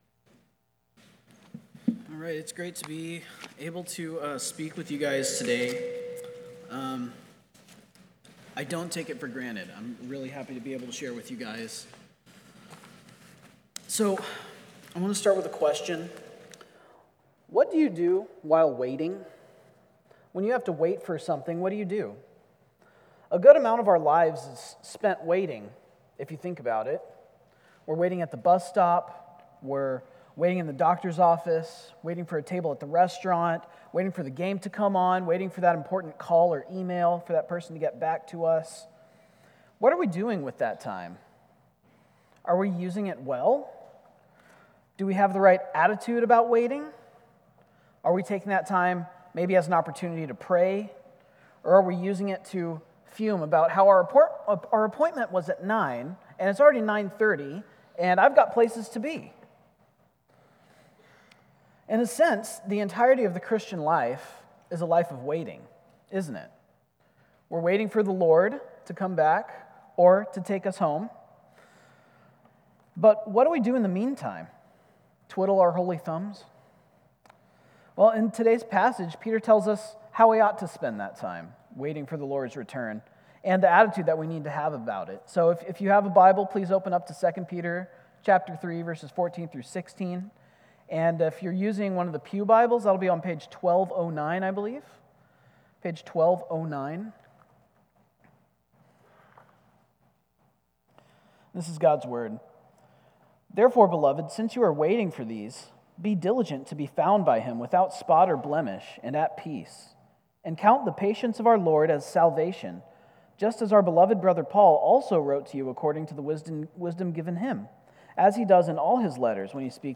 CCBC Sermons 2 Peter 3:14-16 Feb 03 2025 | 00:25:41 1x 00:00 / 00:25:41 Subscribe Share Apple Podcasts Spotify Overcast RSS Feed Share Link Embed